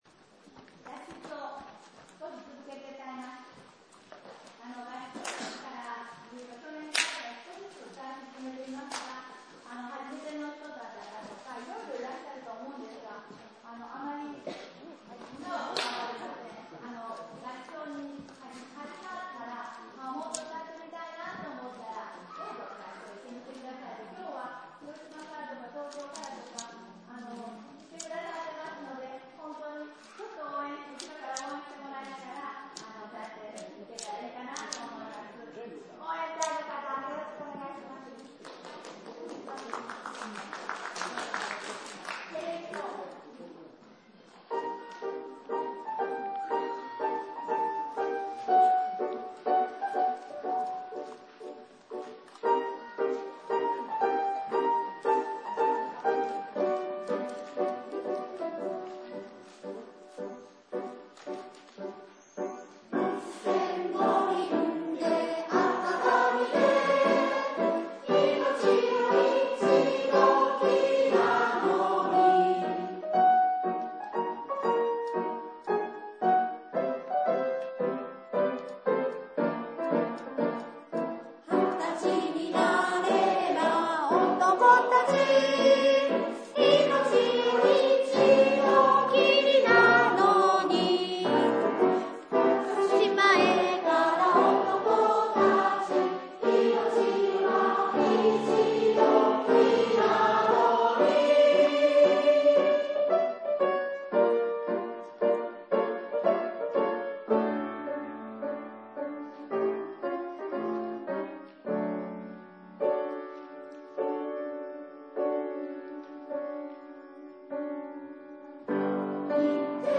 大阪音楽教育の会2015年10月例会での練習(ピアノ